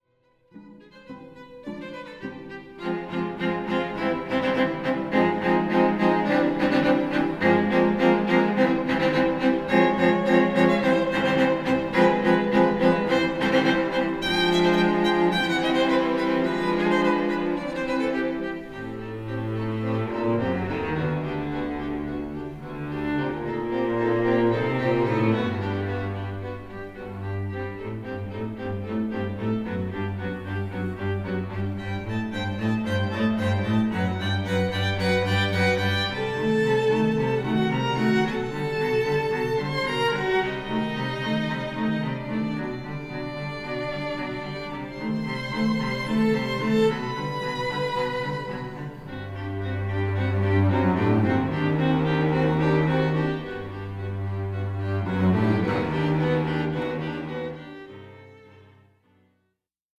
ピアノ
ボーカル